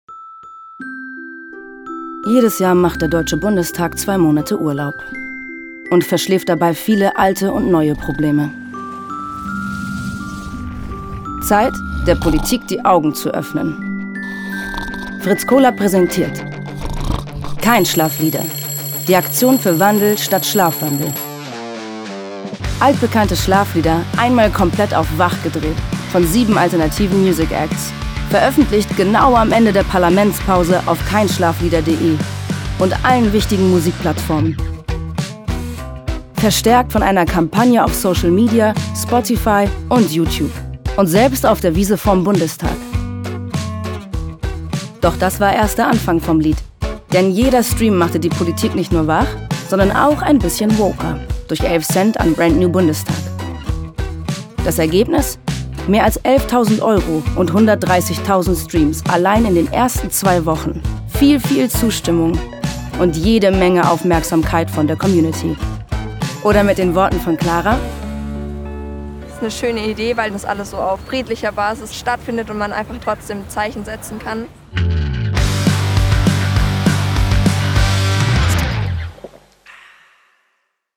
dunkel, sonor, souverän, markant
Mittel minus (25-45)
Norddeutsch
Commercial (Werbung)